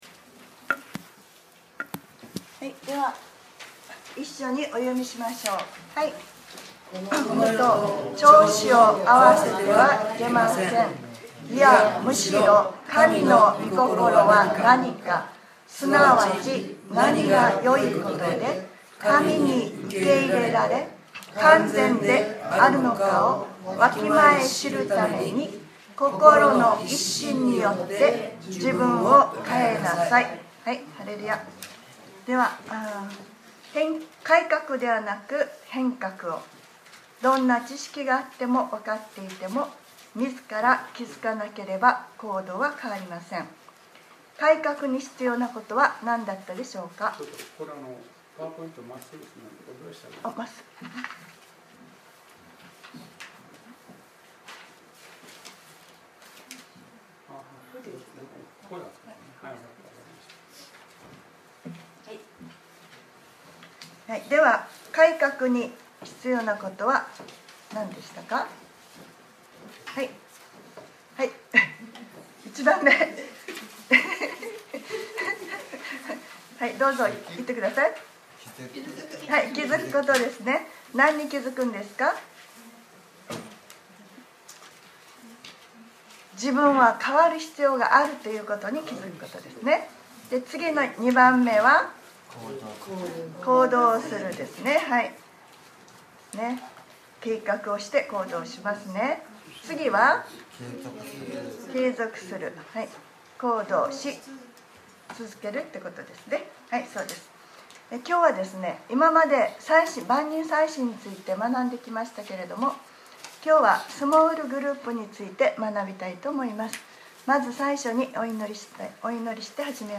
2017年11月19日（日）礼拝説教『変革ｰ11：スモールグループ』